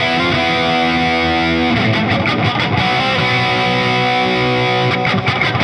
Index of /musicradar/80s-heat-samples/85bpm